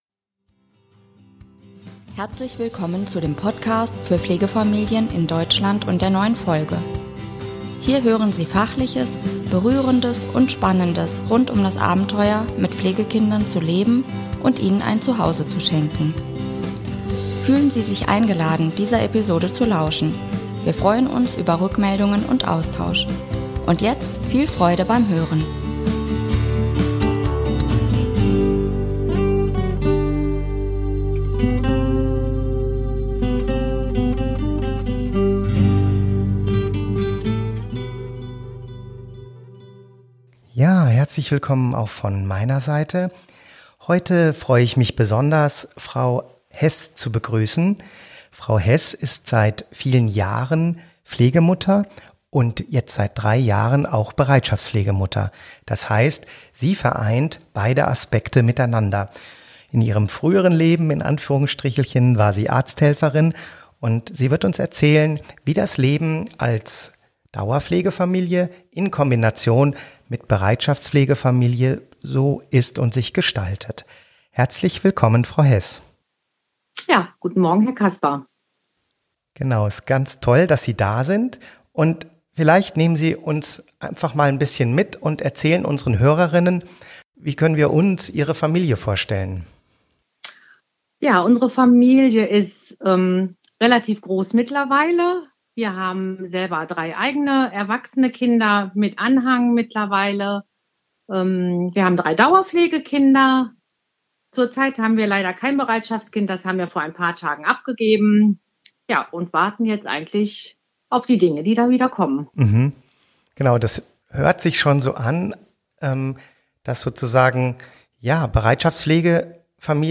Bereitschaftspflegefamilien - eine Pflegemutter erzählt ~ Pflegefamilien Deutschland Podcast